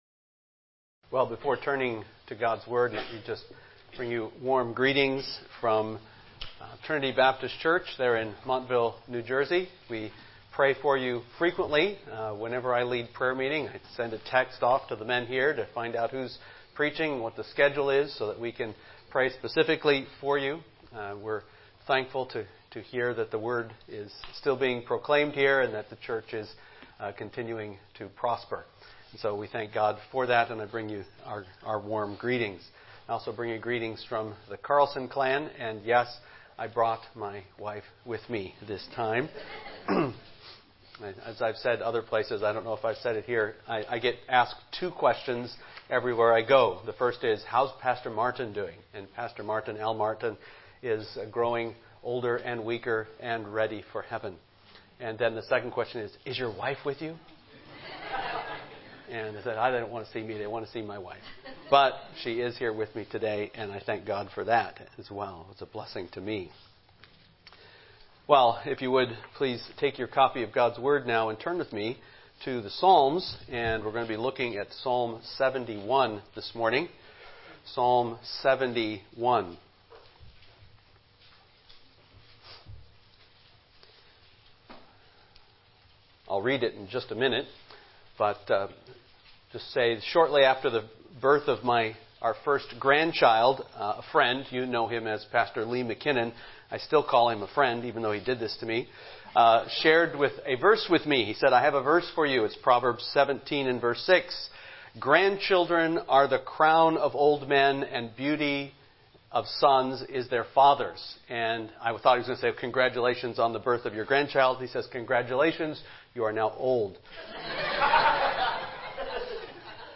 Passage: Psalm 71 Service Type: Morning Worship